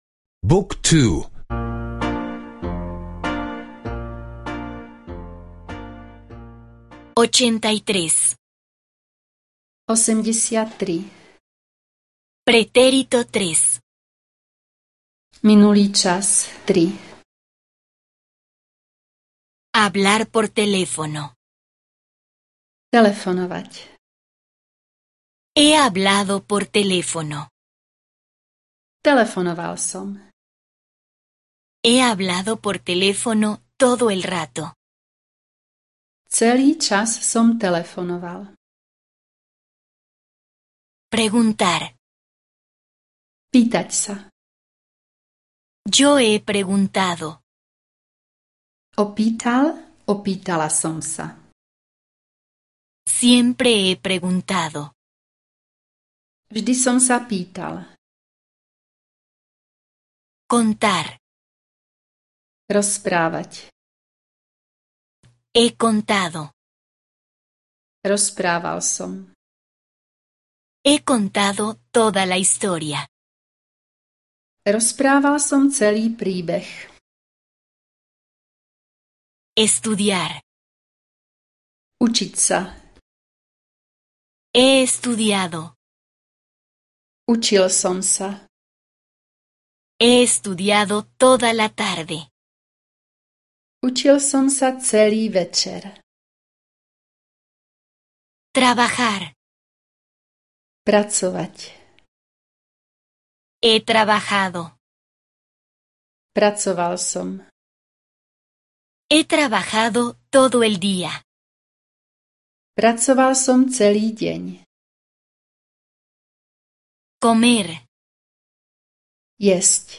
Curso de audio de eslovaco (escuchar en línea)